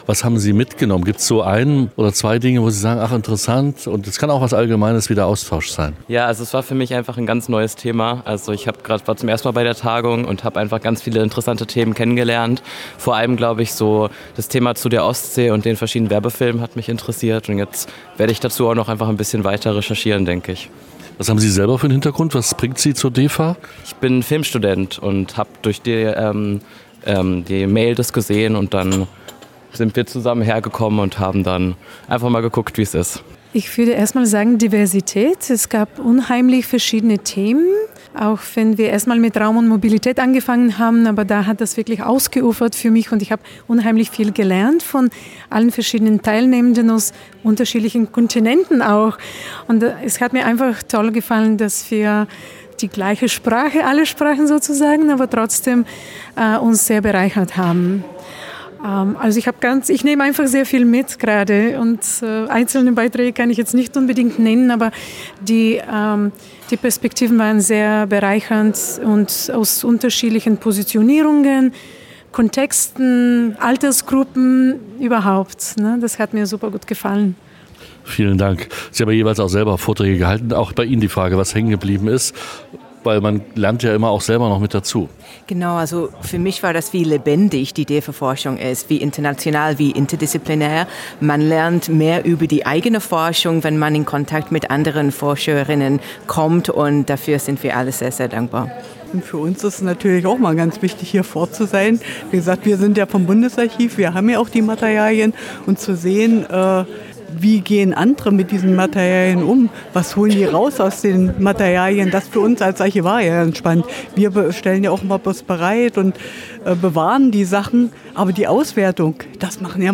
Umfrage unter den Teilnehmenden
DEFA Umfrage unter den Teilnehmenden.mp3